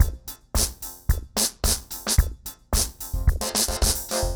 RemixedDrums_110BPM_02.wav